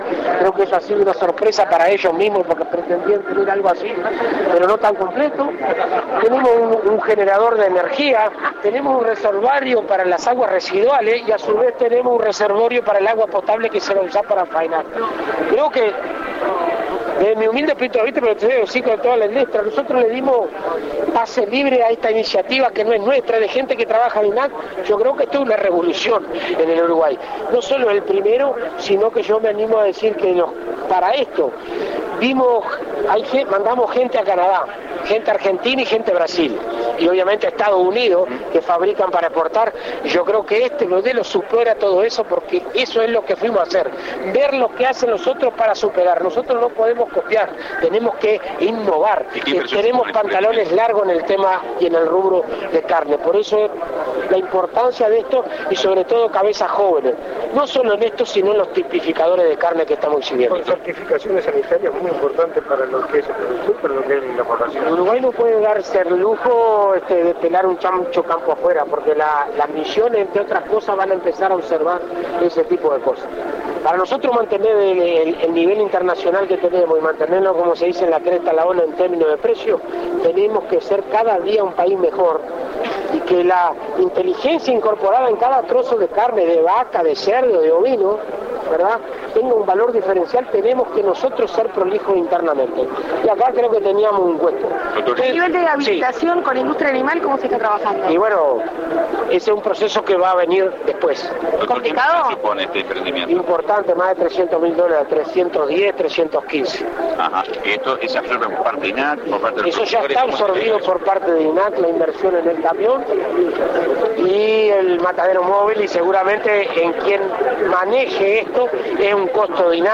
fratti_entrevista2.mp3